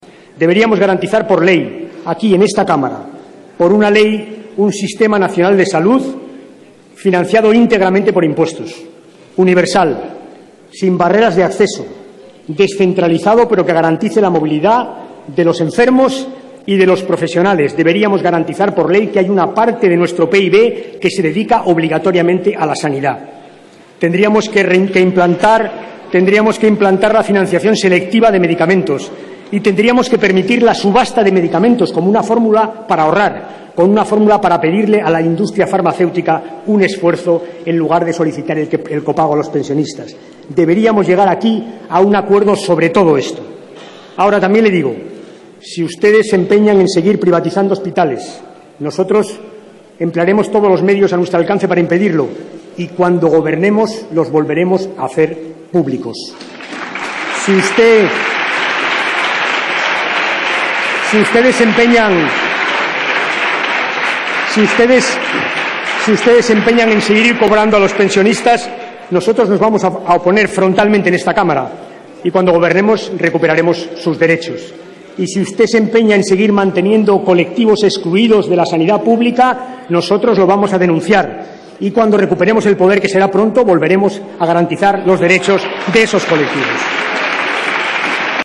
Debate del Estado de la Nación 20/02/2013